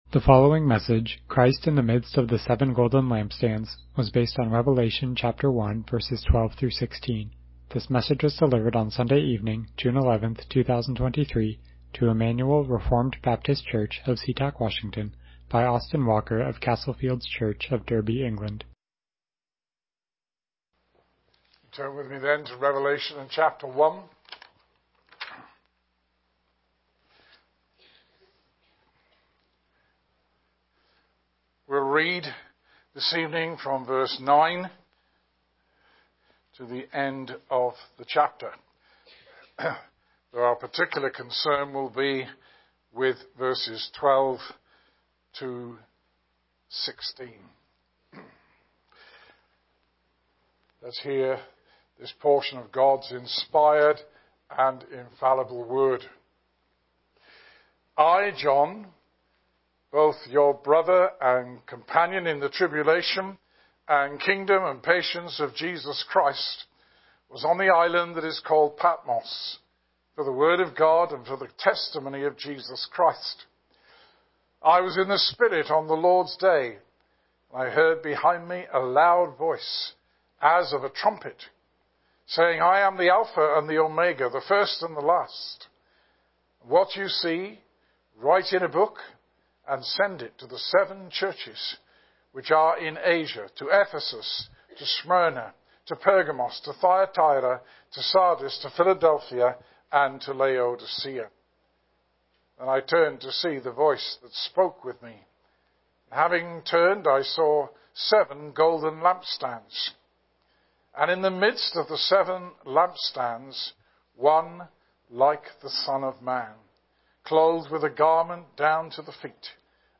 Passage: Revelation 1:12-16 Service Type: Evening Worship « From Grief to Trust Inspiration